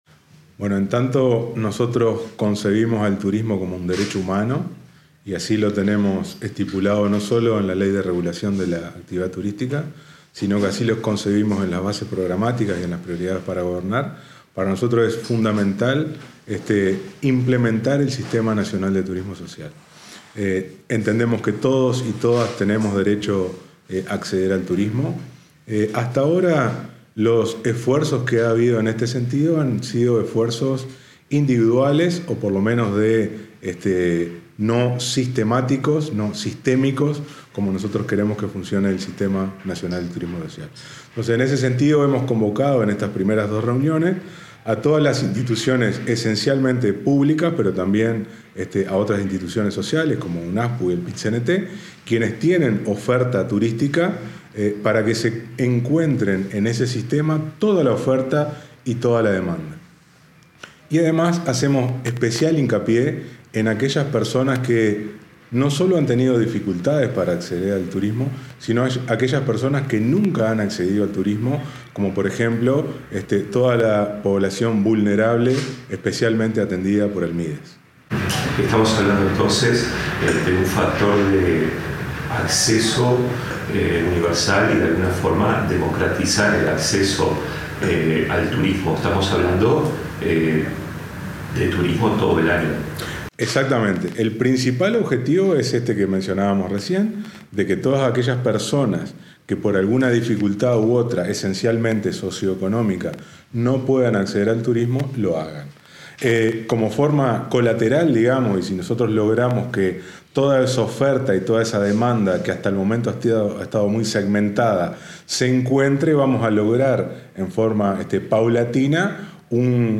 Declaraciones del ministro de Turismo, Pablo Menoni
El Gobierno trabaja para implementar el Sistema Nacional de Turismo Social. El ministro de Turismo, Pablo Menoni, efectuó declaraciones al respecto.